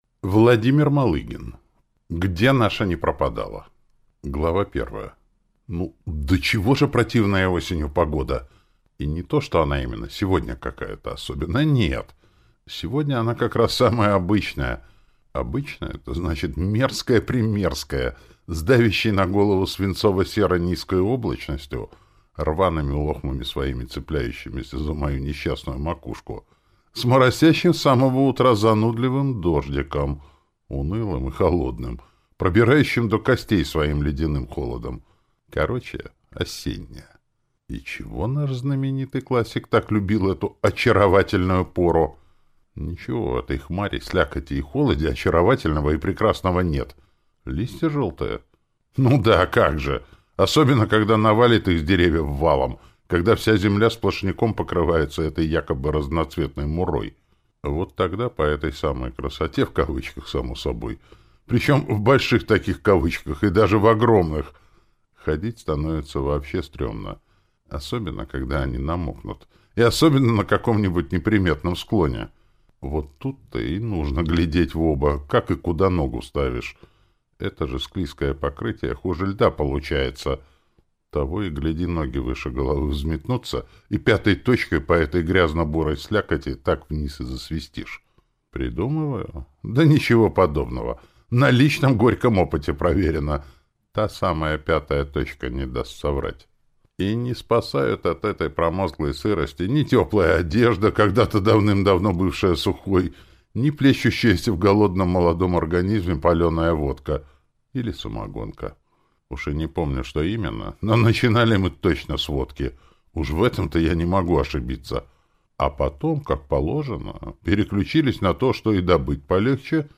Аудиокнига Где наша не пропадала | Библиотека аудиокниг